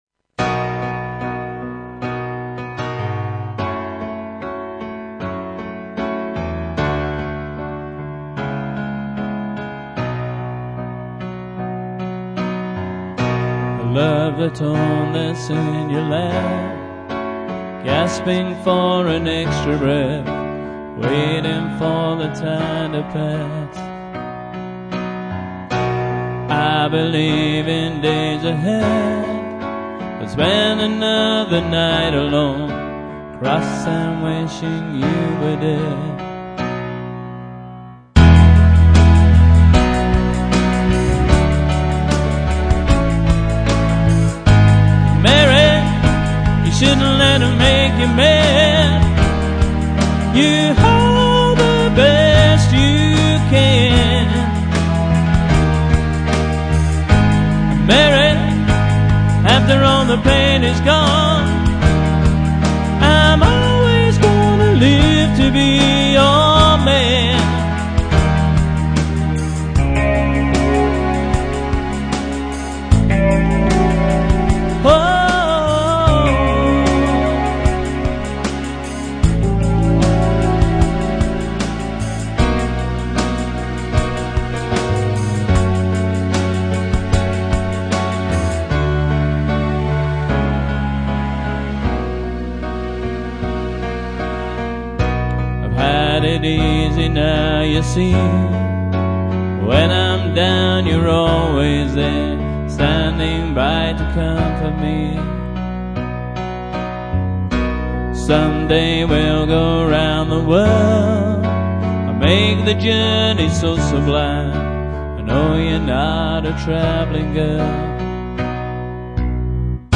Roland RD700 piano, drum machine & vocals